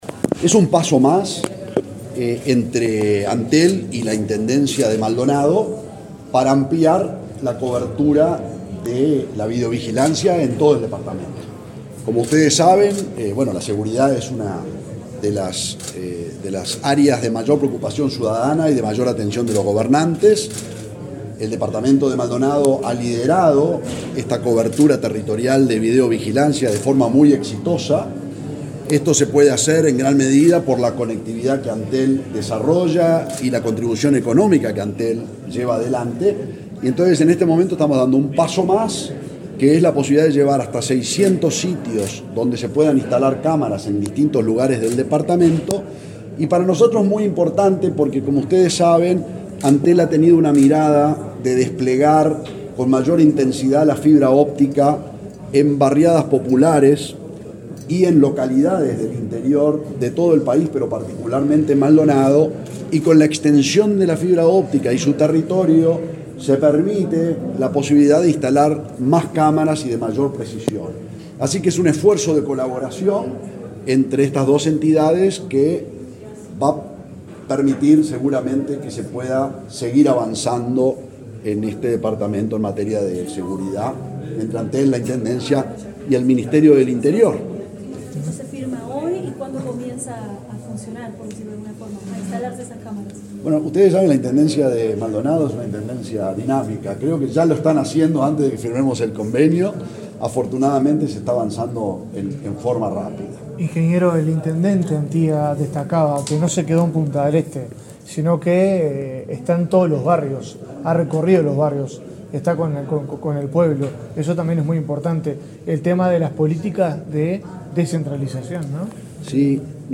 Declaraciones del presidente de Antel, Gabriel Gurméndez
Luego del acuerdo, realizado en el departamento fernandino, el titular del ente, Gabriel Gurméndez, dialogó con la prensa.